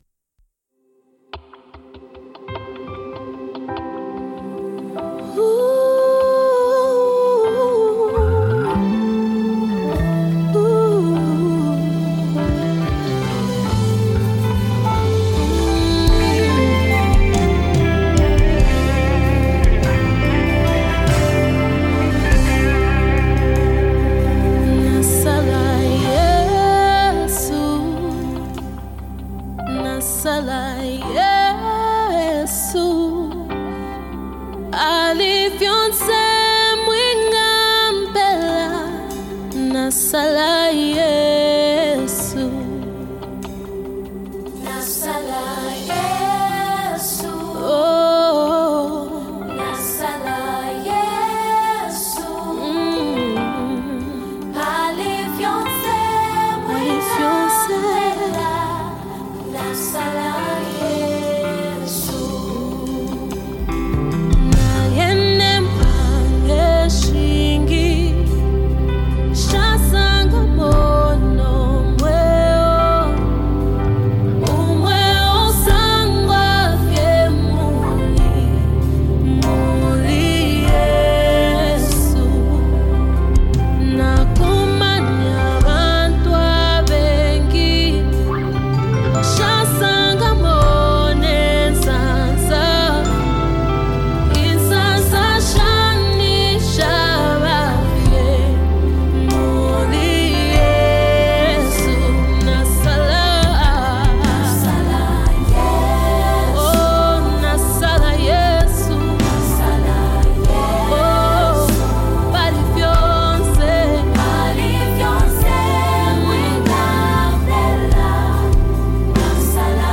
Gospel Music
soul-stirring gospel song
Known for her powerful vocals and heartfelt delivery